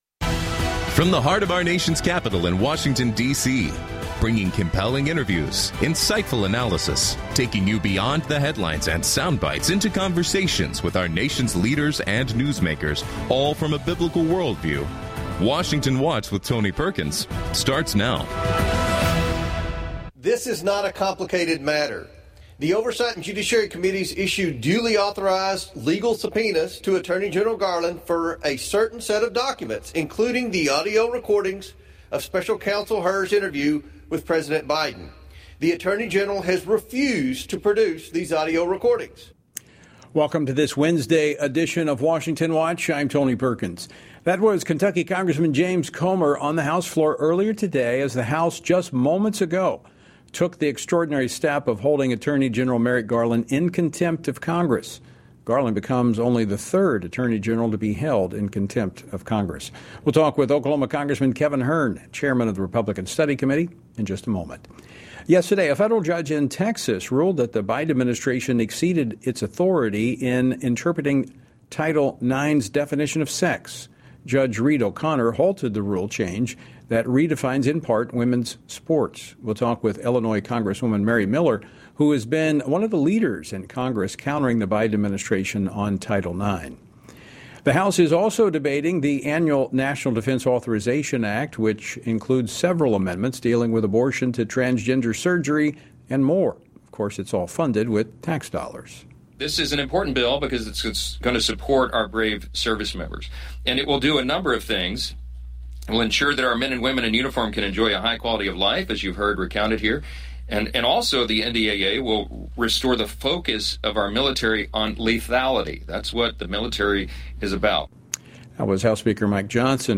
On today’s program: Kevin Hern, U.S. Representative for the 1st District of Oklahoma, discusses the scheduled vote to hold U.S. Attorney General Merrick Garland in contempt of Congress and provides an update on amendments under consideration for the National Defense Authorization Act. Mary Miller, U.S. Representative for the 15th District of Illinois, comments on legislation she introduced to nullify the Biden administration’s radical re-write of Title IX rules and reacts to a federal judge’s rebuke of non-binding guidance for Title IX from the Biden administration.